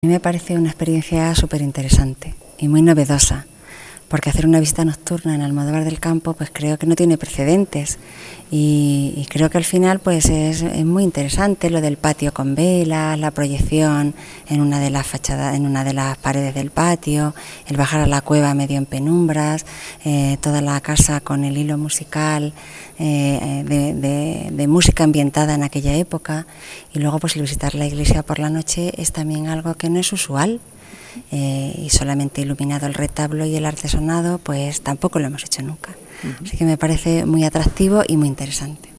Guía.